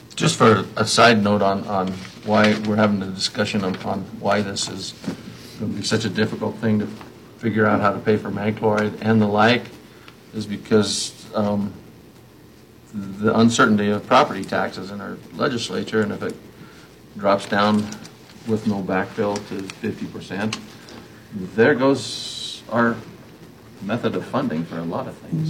Speaking at the February 4th Board meeting, Commissioner John Johnson said the county will have trouble finding the money to pay for road maintenance, specifically magnesium chloride.